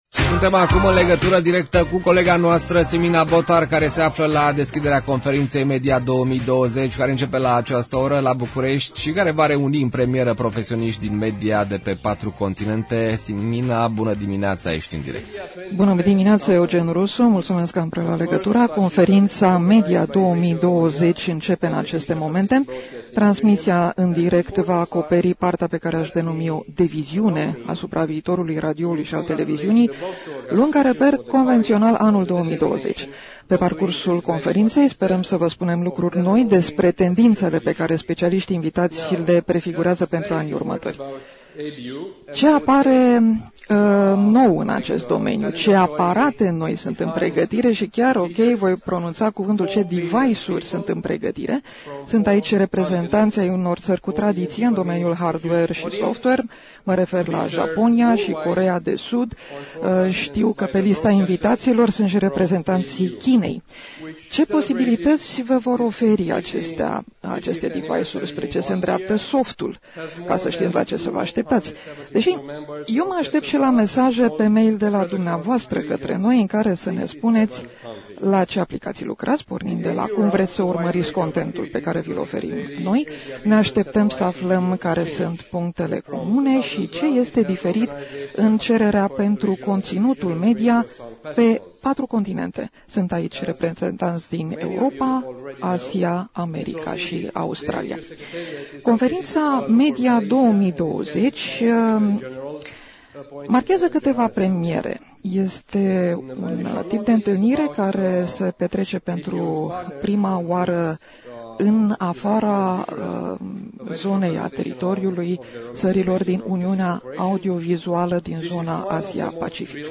Au început lucrările conferinței ABU Media 2020 organizată de Radio România.
Deschiderea conferinței a fost difuzată în direct la Radio România Actualități.